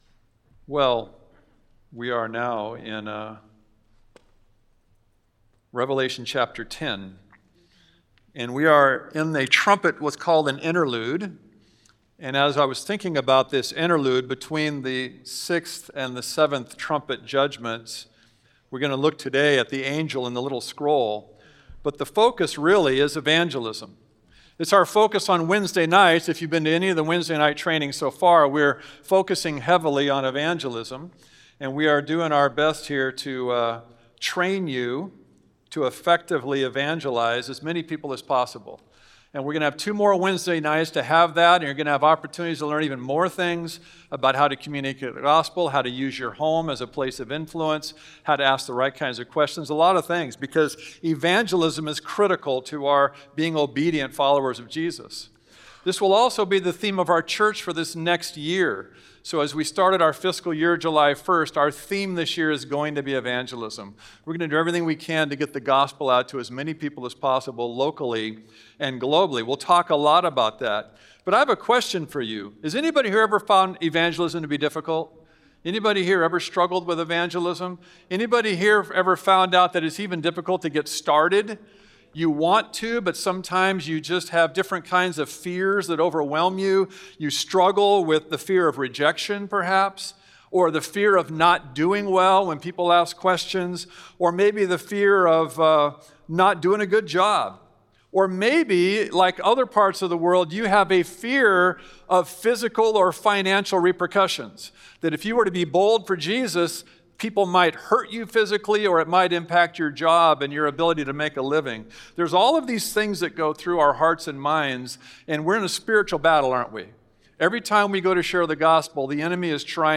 Service Type: Sunday Worship Service